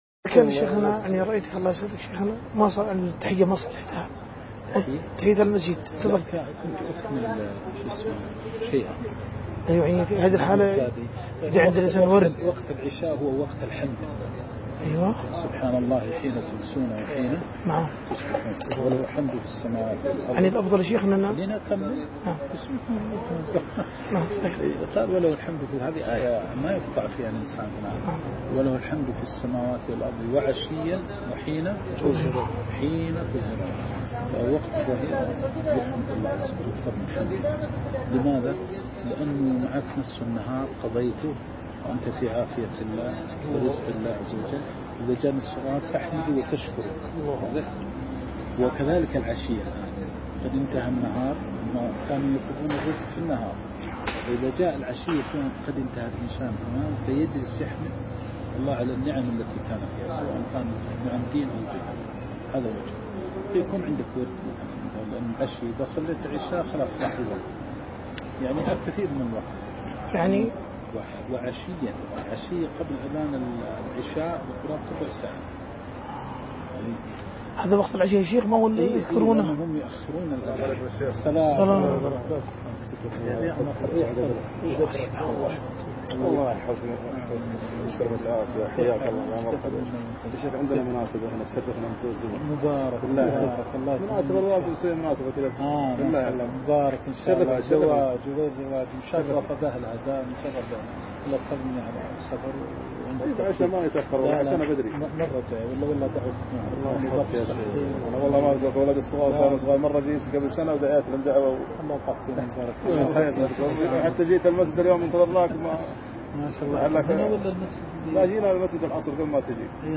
سؤال عن تحية المسجد ورد الشيخ عن وقت الحمد